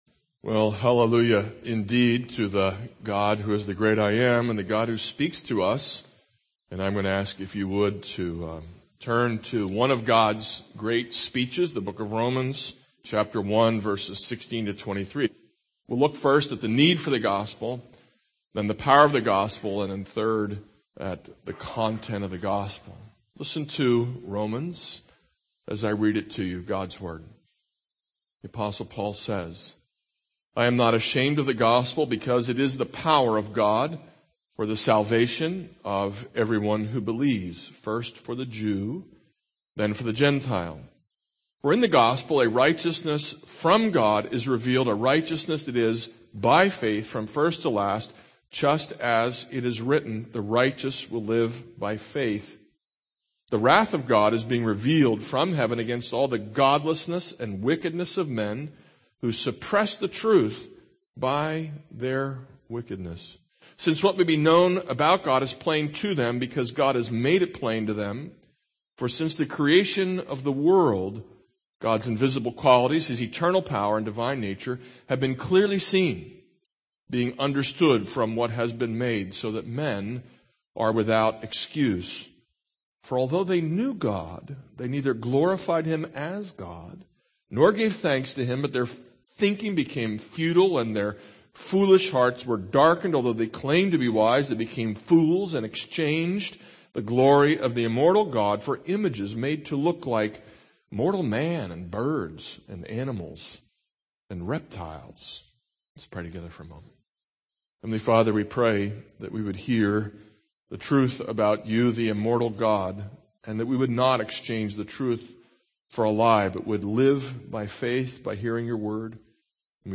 This is a sermon on Romans 1:16-23.